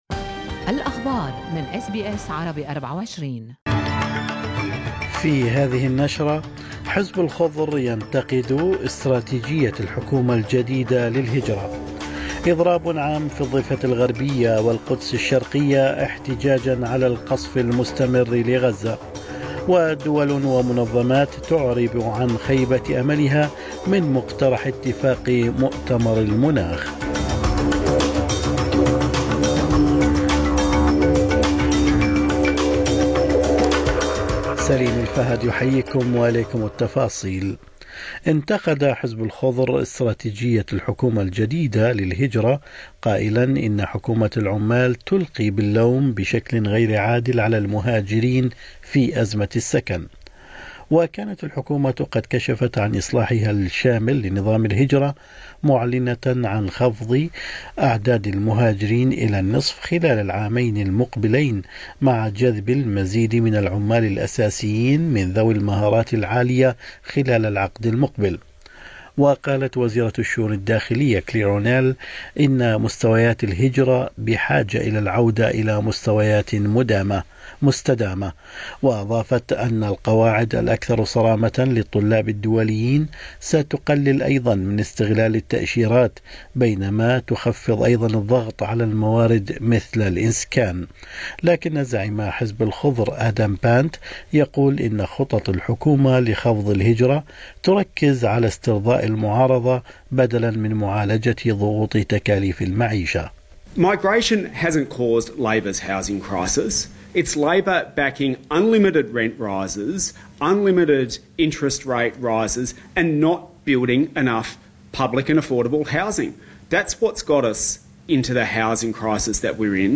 نشرة أخبار الصباح 12/12/2023